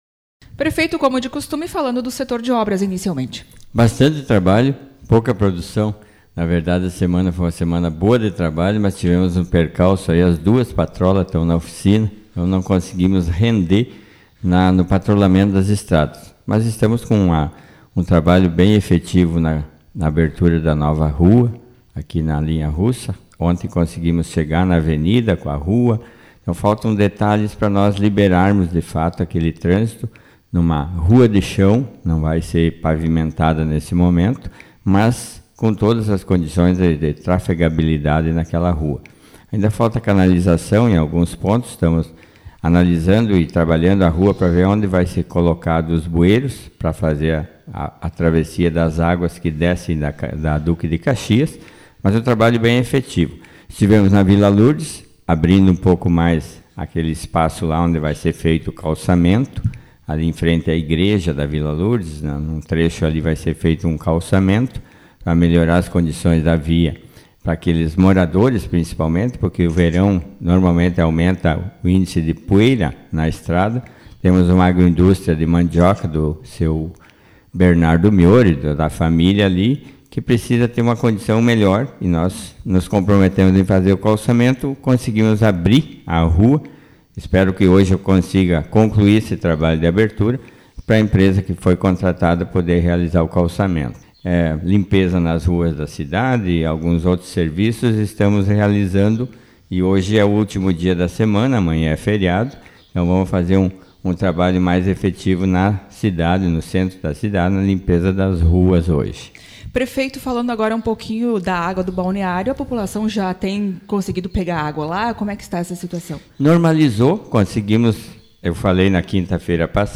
Prefeito de Iraí atualiza informações sobre atividades do Executivo Autor: Rádio Marabá 14/11/2024 Manchete O prefeito de Iraí, Antonio Vilson Beranrdi, participou nesta manhã do programa Café com Notícias e ressaltou as ações do Executivo na agricultura, obras e saúde. Acompanhe a entrevista